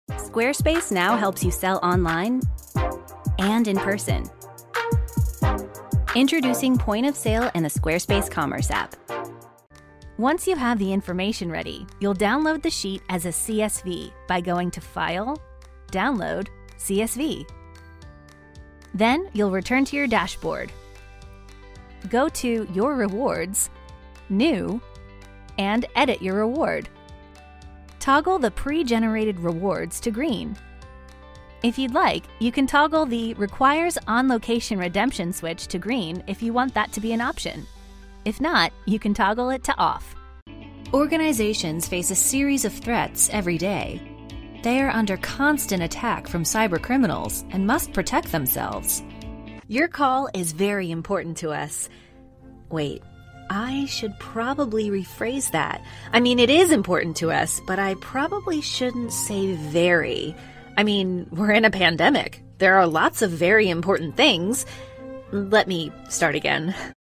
Female Voice Over, Dan Wachs Talent Agency.
Bright, young, edgy, real person.
Corporate